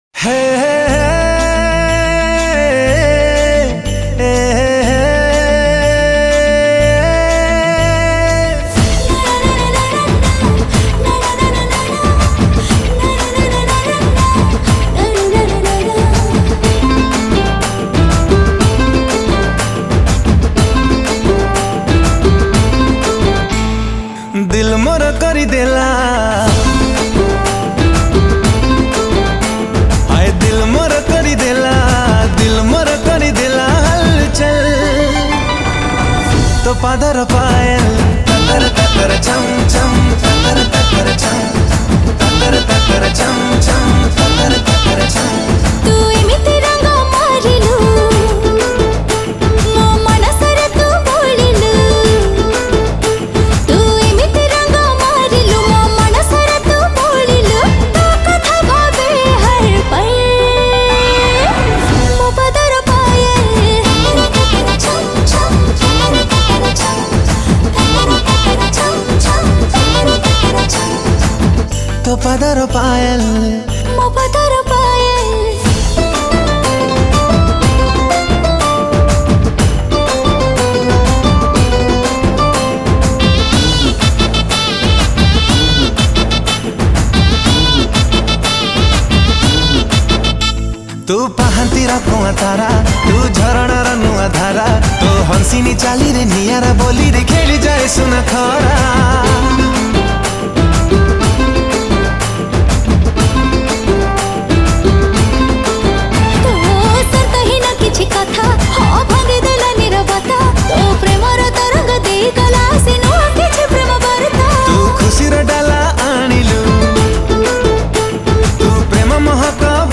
Category: New Odia Romantic Album Songs 2022